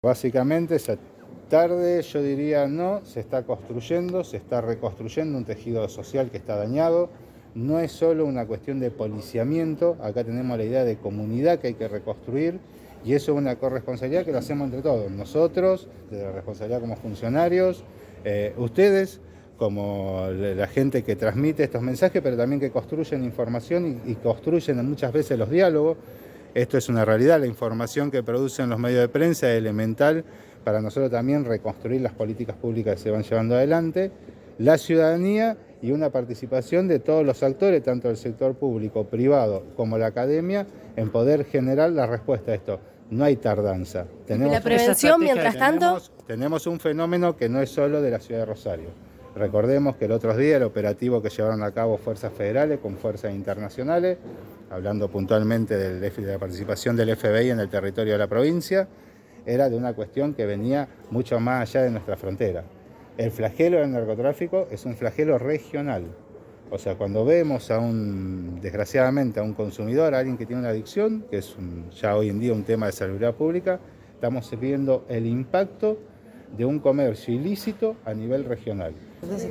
Tras el encuentro, Edgardo Glavinich, subsecretario de Políticas de Seguridad Pública, habló con el móvil de Cadena 3 Rosario, en Siempre Juntos, y remarcó: “El flagelo del narcotráfico es regional”.